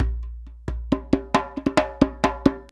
089DJEMB03.wav